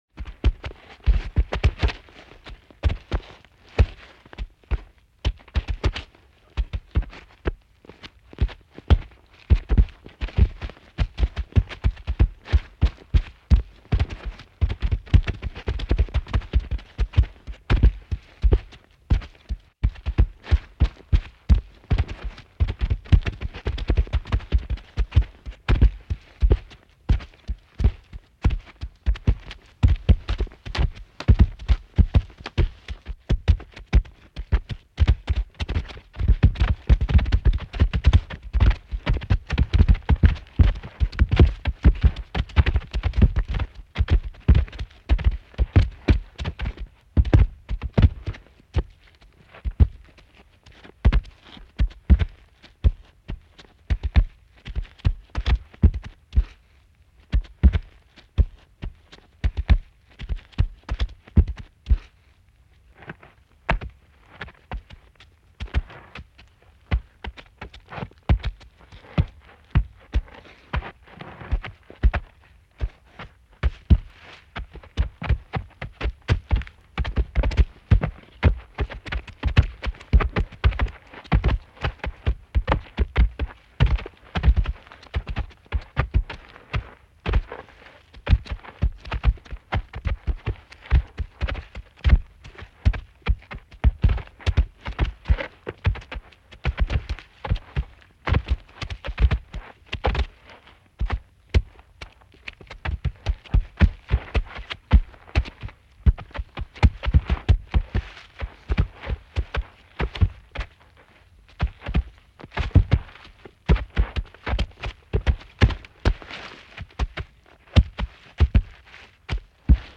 دانلود آهنگ فیل 5 از افکت صوتی انسان و موجودات زنده
جلوه های صوتی
دانلود صدای فیل 5 از ساعد نیوز با لینک مستقیم و کیفیت بالا